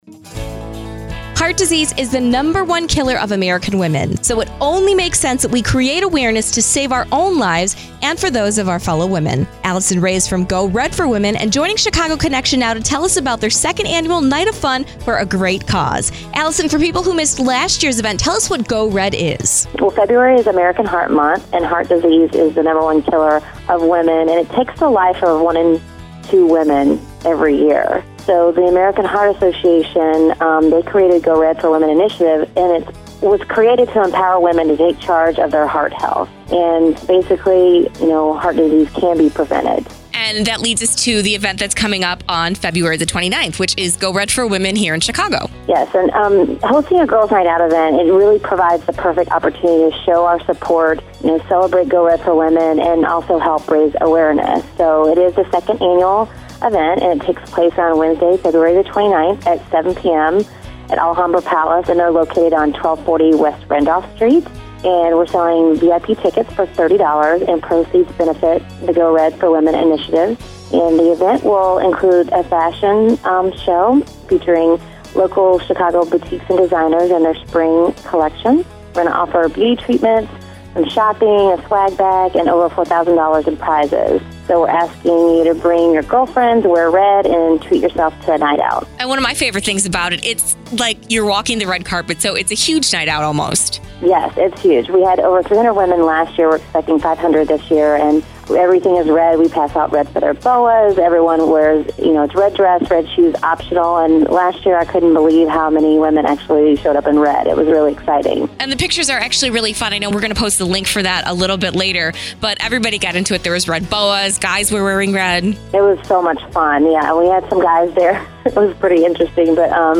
CHICAGO CONNECTION – GO RED INTERVIEW
CHICAGO-CONNECTION-GO-RED-INTERVIEW.mp3